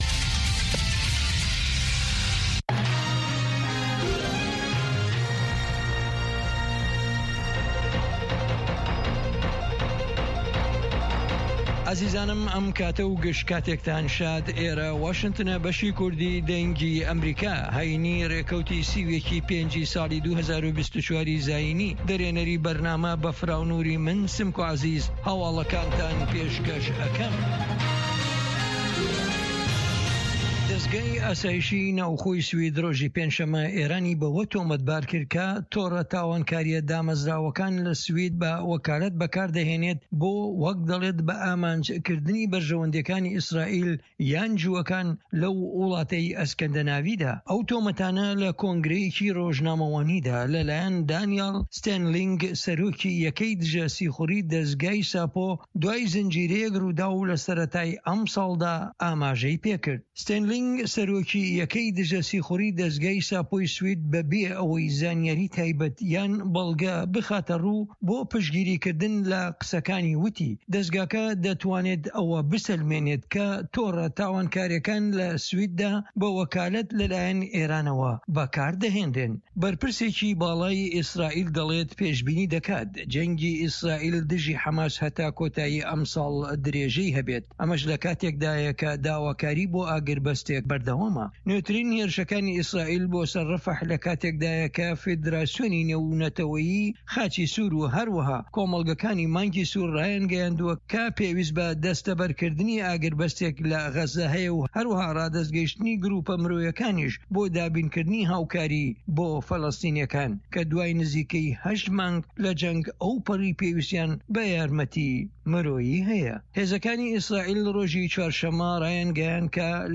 هەواڵە جیهانیـیەکان لە دەنگی ئەمەریکا